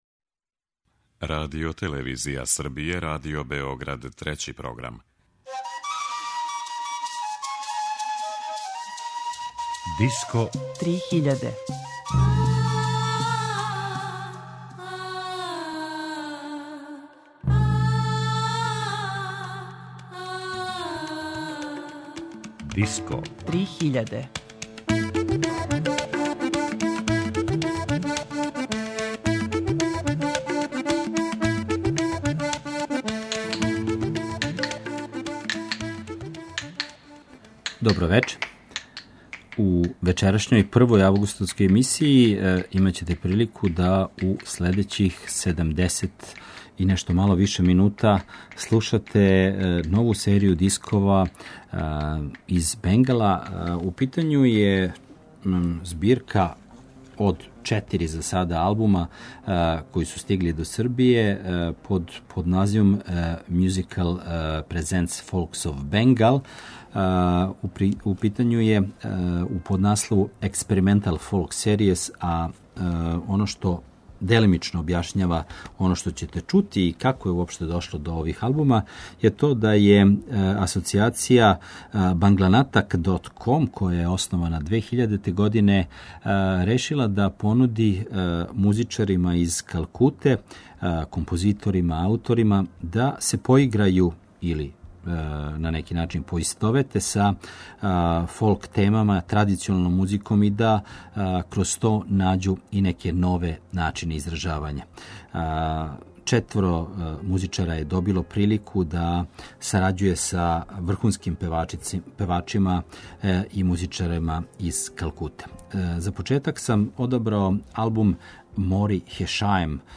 Музика Бенгала
Богата и вишеслојна традиција Западног Бенгала ће овај пут бити представљена кроз серију албума који се баве нешто експерименталнијим фолк звуком, како то сами музичари називају.